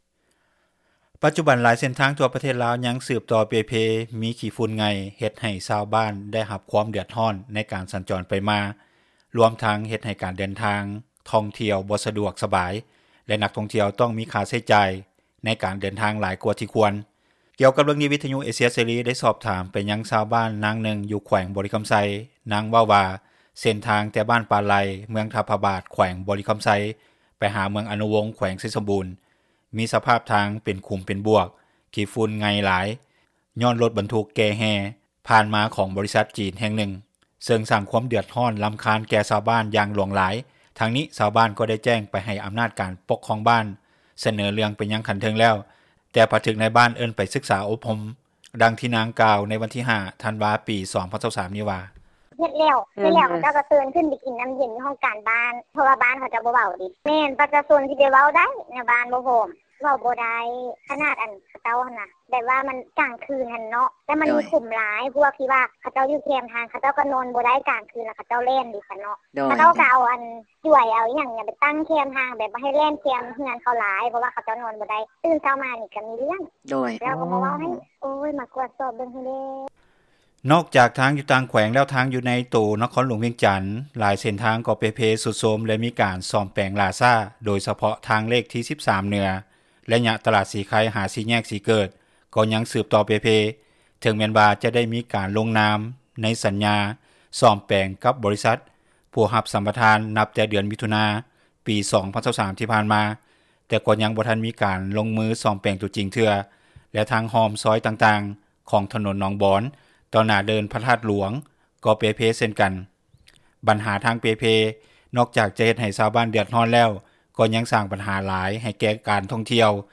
ກ່ຽວກັບເຣື່ອງນີ້, ວິທຍຸເອເຊັຽເສຣີໄດ້ສອບຖາມຊາວບ້ານ ນາງນຶ່ງຢູ່ແຂວງບໍຣິຄໍາໄຊ.